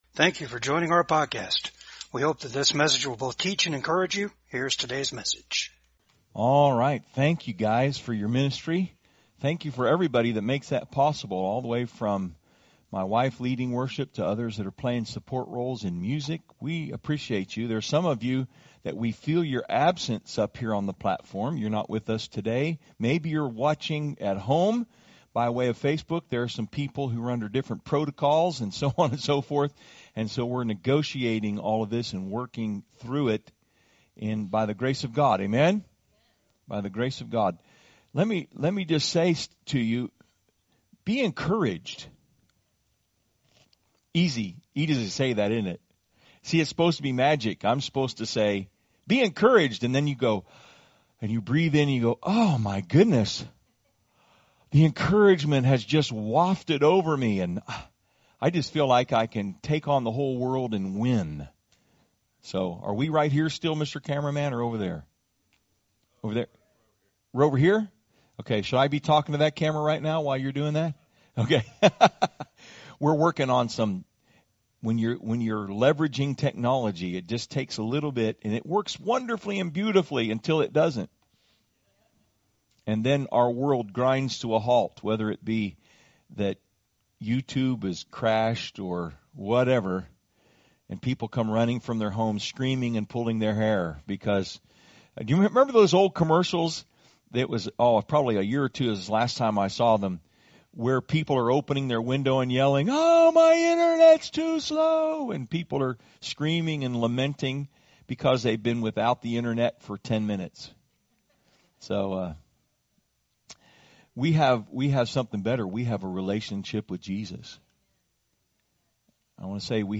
Service Type: VCAG SUNDAY SERVICE
Topics: testimony